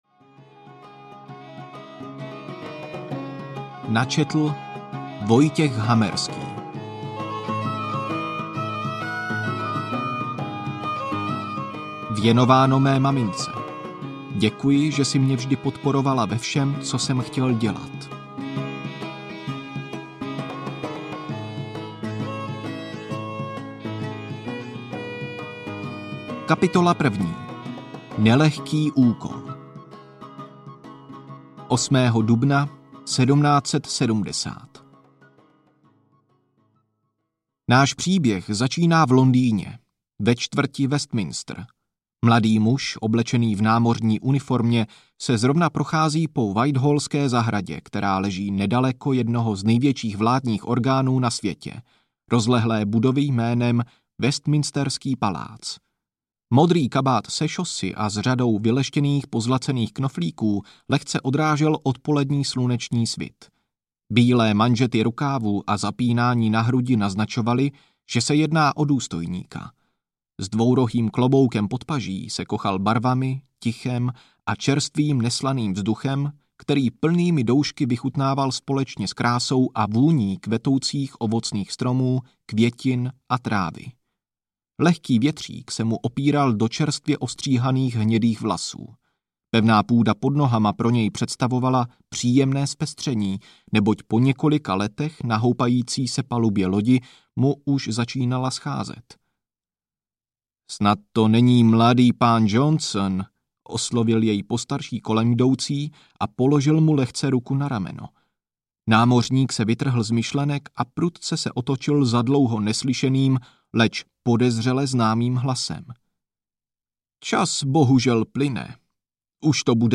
Vykoupení Černé vlaštovky audiokniha
Ukázka z knihy
vykoupeni-cerne-vlastovky-audiokniha